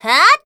assassin_w_voc_attack02_a.ogg